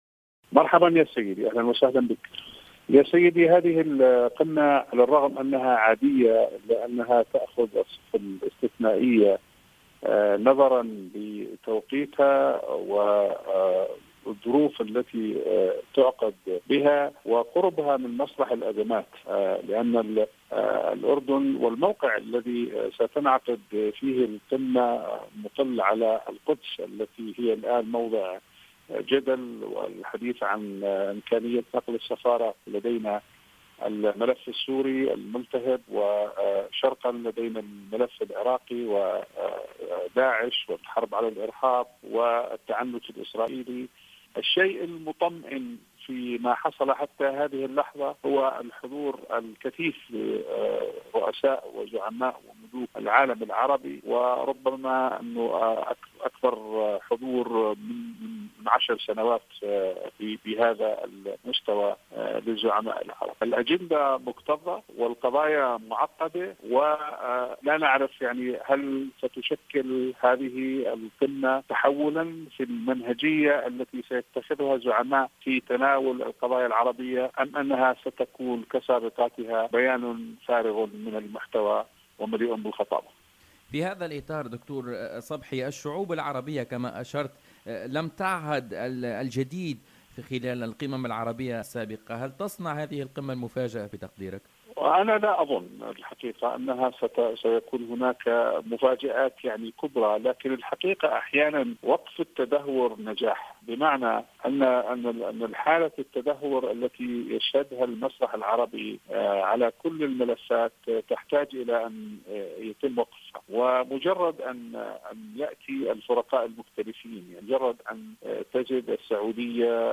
و لفت ربيحات في تصريح خاص للجوهرة "أف أم" إلى أنّ المطمئن في هذه القمة، تسجيل أكبر حضور للزعماء العرب منذ أكثر من 10 سنوات، مشيرا إلى أنّ أجندة الدورة الثامنة و العشرين للقمة العربية مكتظة و القضايا معقدّة و تسآل هل ستُشكل هذه القمة تحوّلا في المنهجية التي سيعتمدها الزعماء العرب في تناول القضايا العربية، أم ستنتهي كسابقاتها ببيان فارغ من المحتوى و مليء بالخطابة حسب تعبيره.